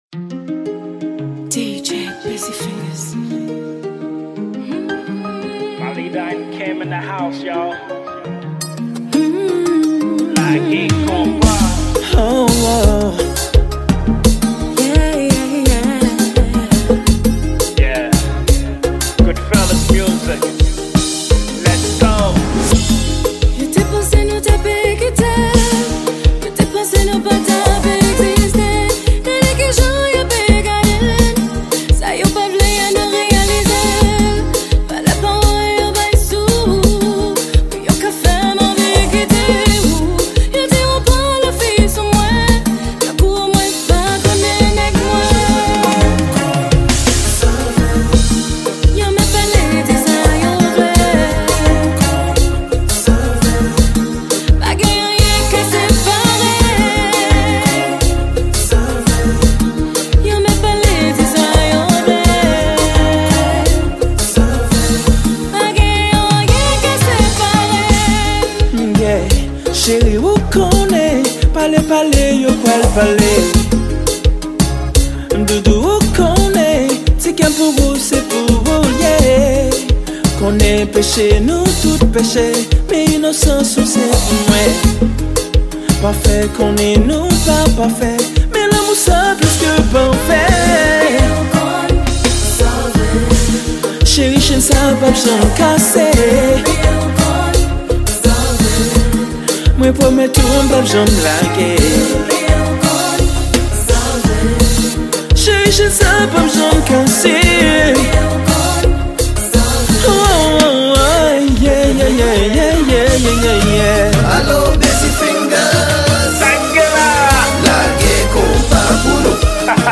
Genre : KONPA